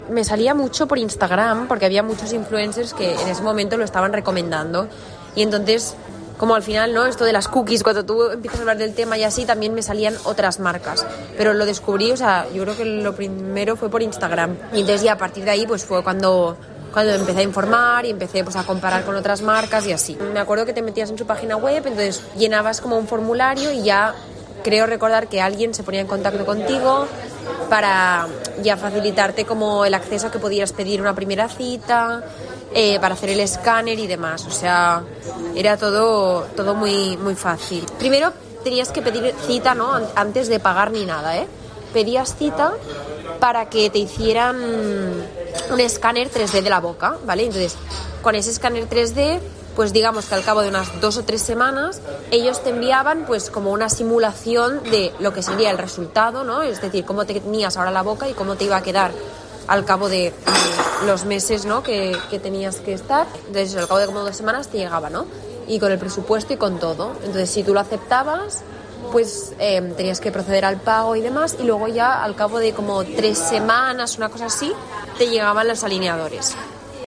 paciente de este tipo de clínicas online